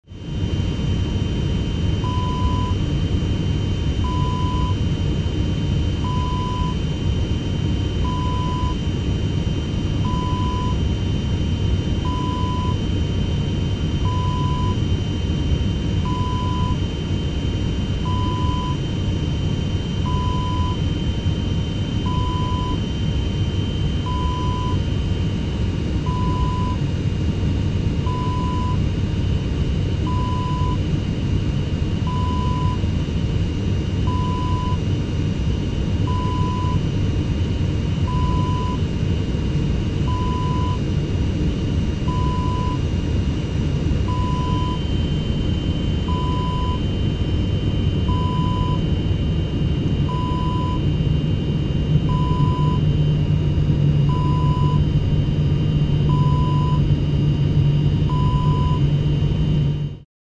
Airplane Interior Background Ambience Wav #2
Description: Airplane cabin ambience
Properties: 48.000 kHz 16-bit Stereo
A beep sound is embedded in the audio preview file but it is not present in the high resolution downloadable wav file.
airplane-interior-preview-2.mp3